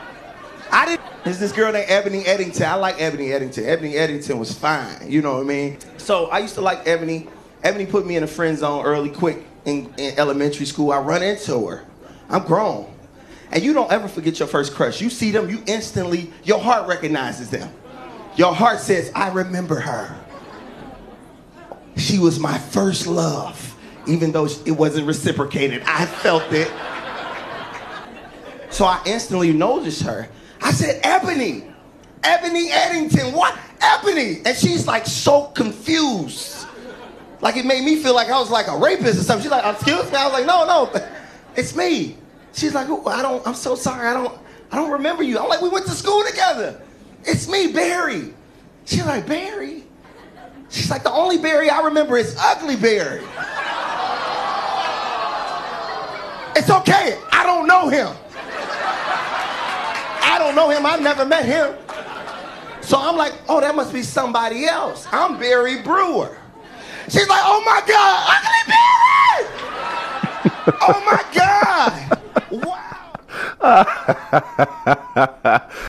Stand up comedy